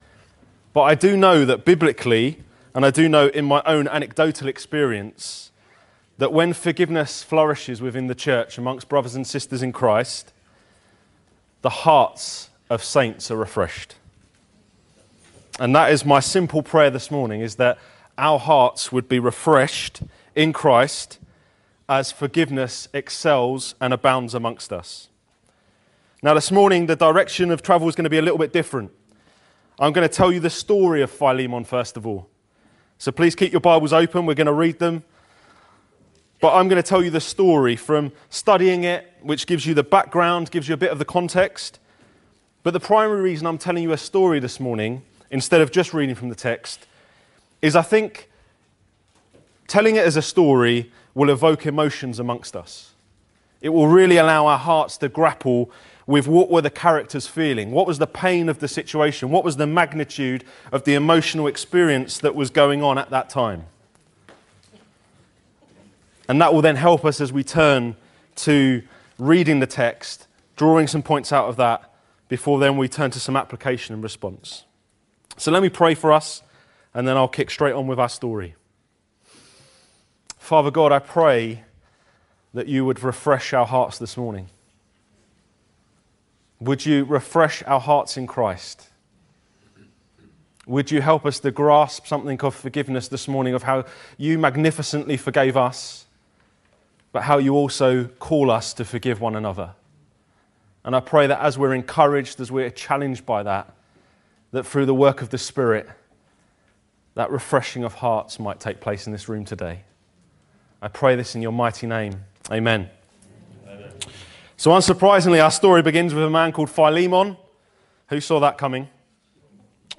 This sermon encourages all Christian’s to imitate our amazing God excelling in forgiveness.